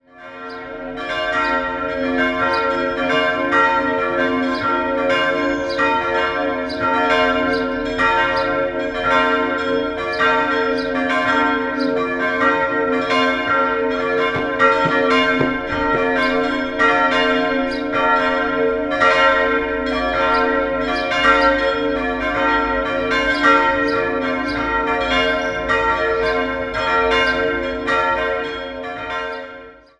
Liezen, Stadtpfarrkirche St. Vitus - Vollgeläut
Das Kircheninnere erhielt ein Kreuzrippengewölbe. 4-stimmiges Geläut: b'-c''-d''-g'' D ie drei größeren Glocken wurden 1922 von den Böhlerwerken in Kapfenberg gegossen. Die kleinste stammt von Johann Feltl aus dem Jahr 1830.